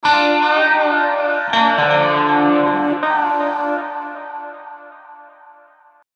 Tag: 80 bpm Folk Loops Guitar Electric Loops 1.01 MB wav Key : Unknown